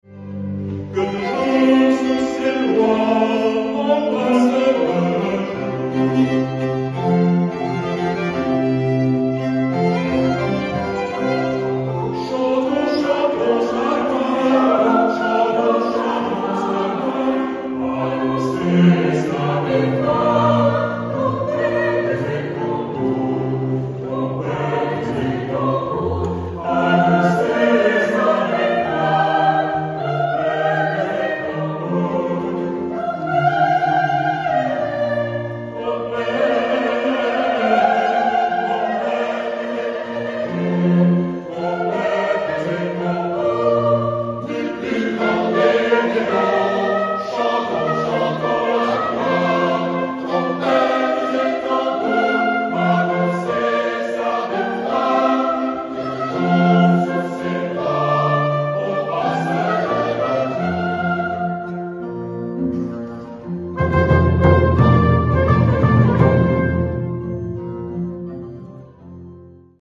Final-David_Jonathas-concert-2024_cut.mp3